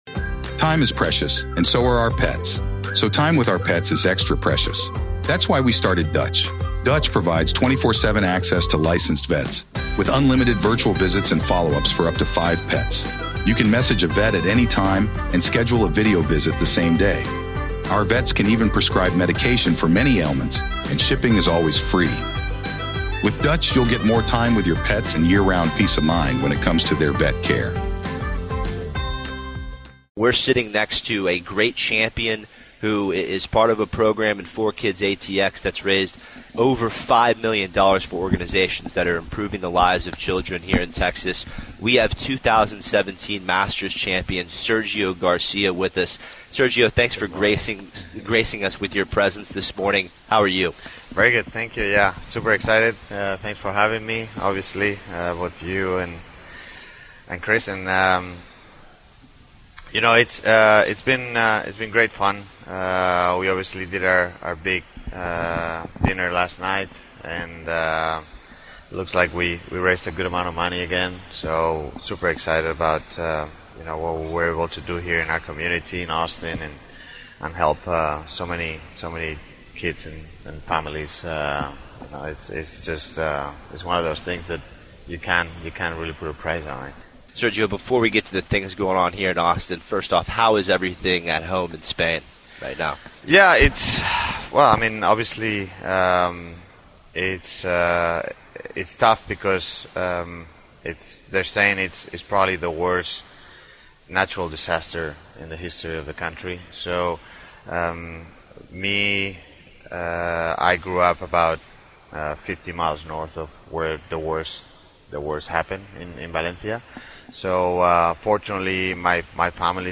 2017 Masters Champion Sergio Garcia joins the show LIVE from Barton Creek!